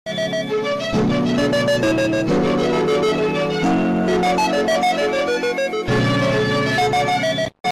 ai baby meme Meme Sound Effect
ai baby meme.mp3